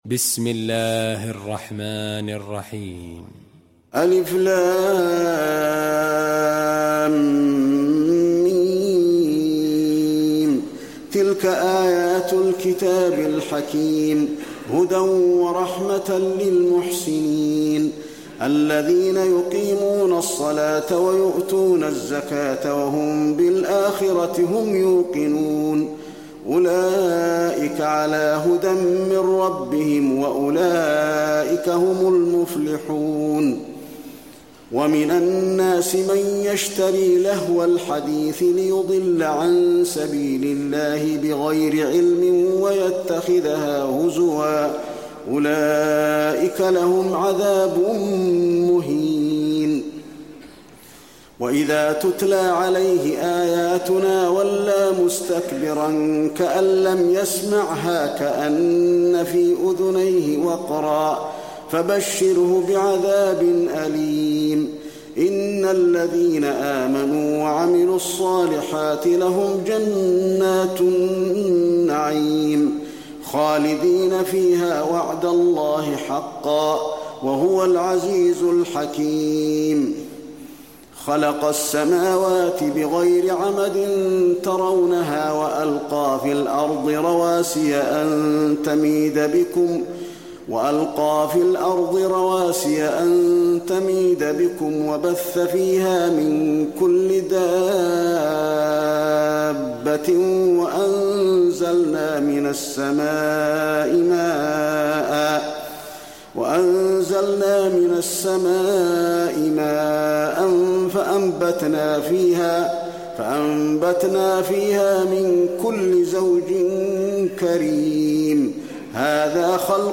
المكان: المسجد النبوي لقمان